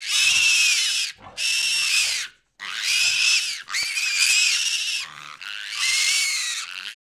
BABY PIG 00L.wav